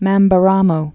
(mămbə-rämō)